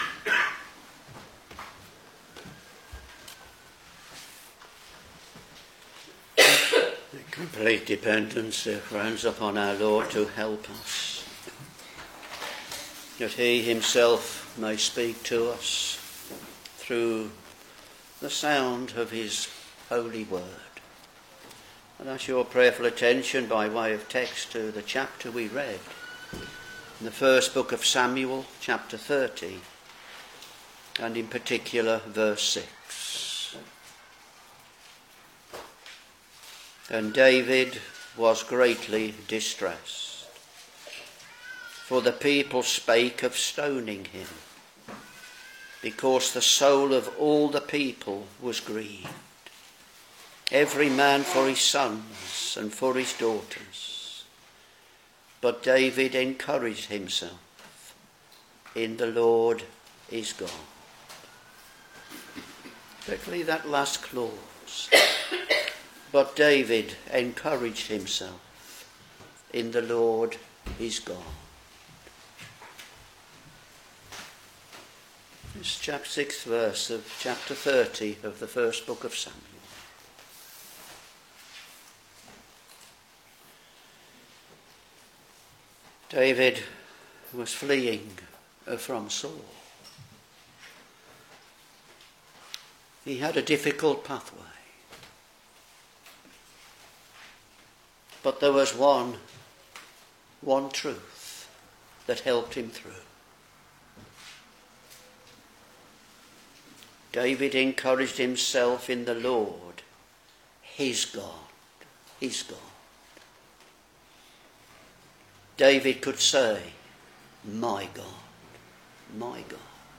Sermons 1 Samuel Ch.30 v.6 (last clause in particular)